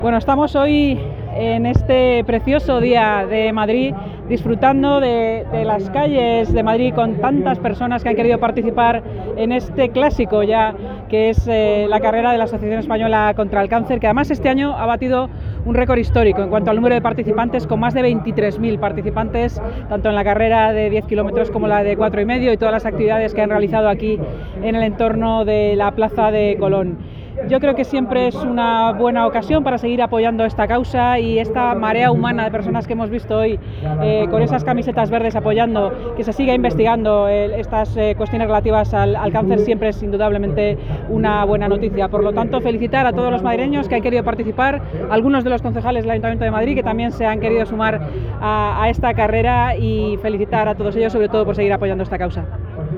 Nueva ventana:Declaraciones de la vicealcaldesa de Madrid y alcaldesa en funciones, Inma Sanz, durante su participación en la XI Carrera Madrid en Marcha Contra el Cáncer